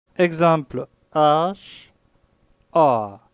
"a" bref